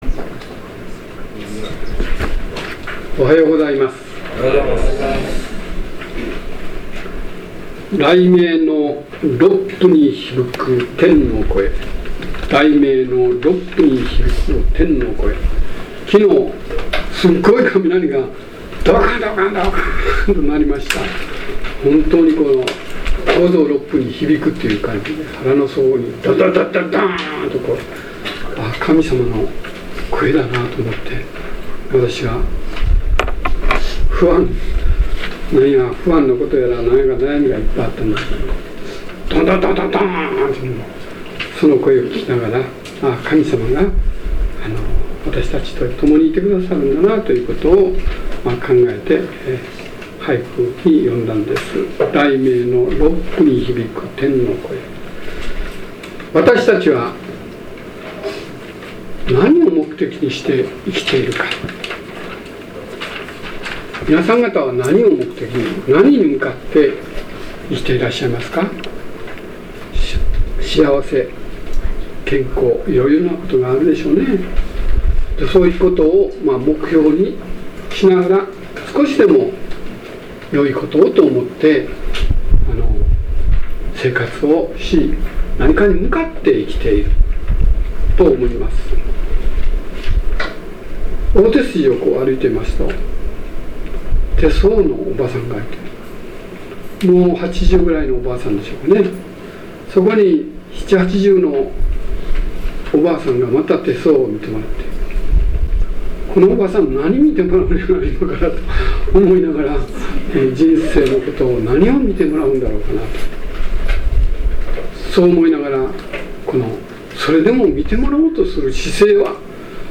説教要旨 2013年7月14日 神の御業を見よ | 日本基督教団 世光教会 京都市伏見区